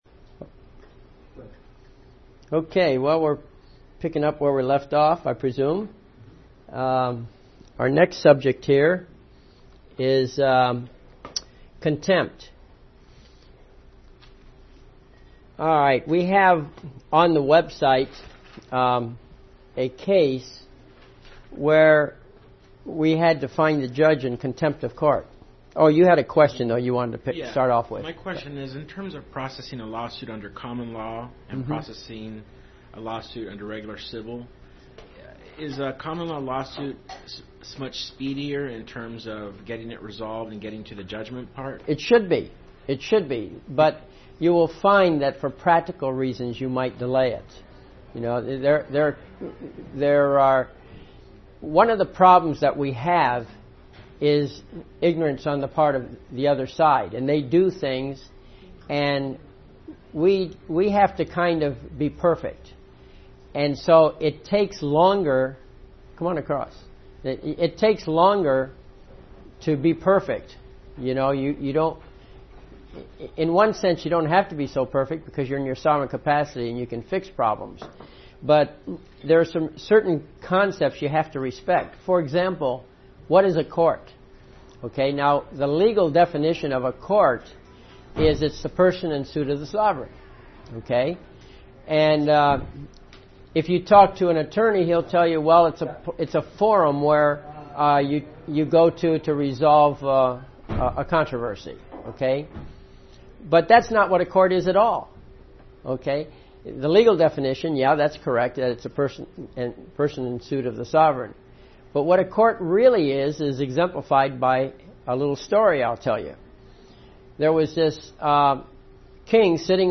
A collection of lectures